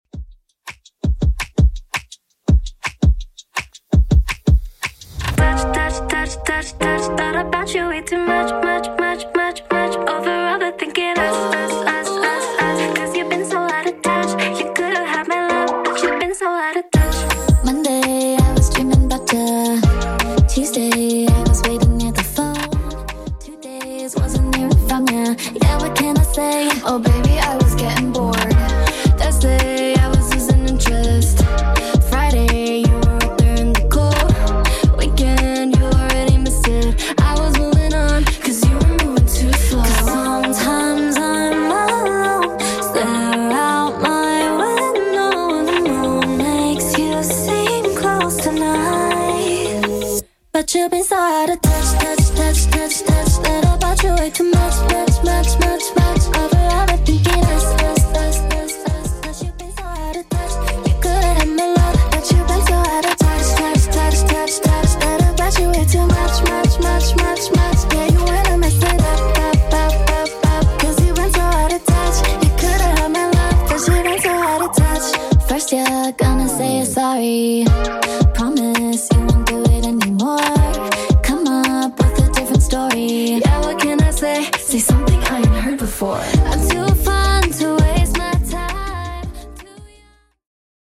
BPM: 83 Time